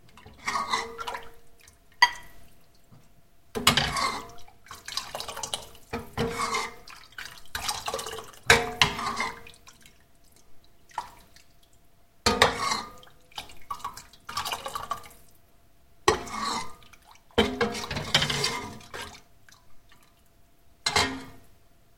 Звук пельменей: перекладываем из кастрюли в тарелку